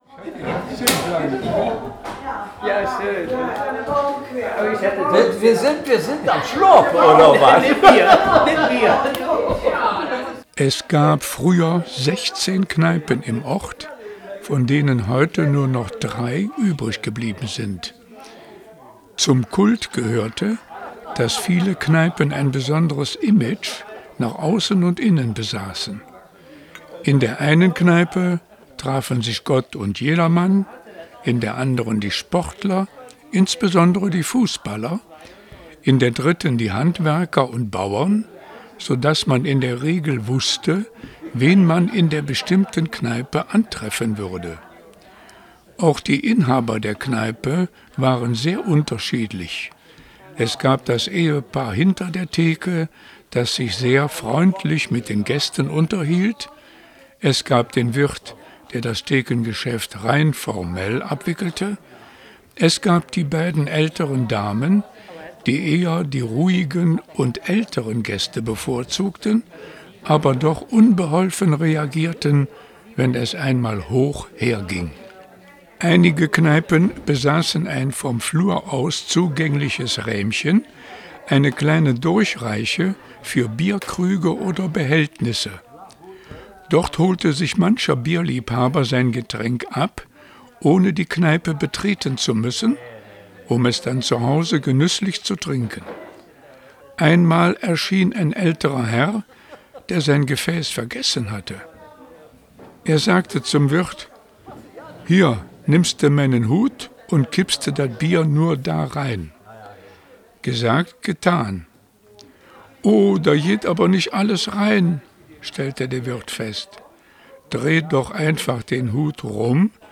Kneipengespraech.mp3